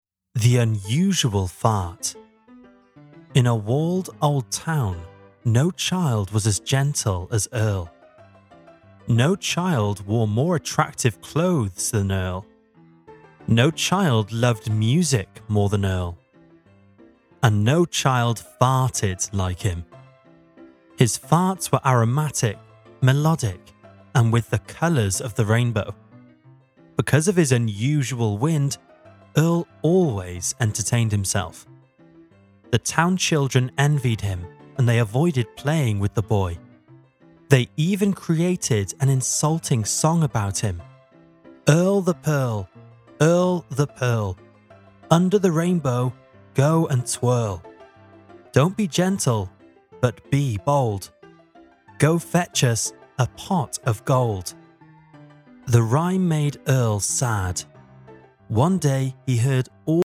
Audio Sample of The Unusual Fart